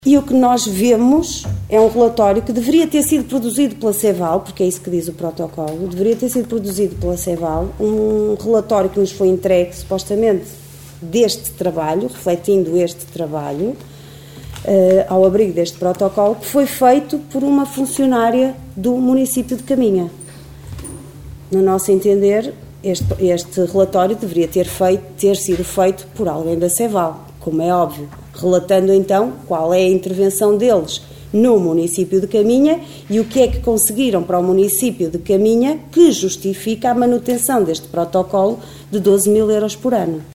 O trabalho que está a ser desenvolvido no concelho de Caminha pela CEVAL, Confederação Empresarial do Alto Minho, a quem a Câmara concede um apoio anual de 12 mil euros, foi criticado na reunião do executivo de ontem pela vereadora da Coligação OCP, Liliana Silva.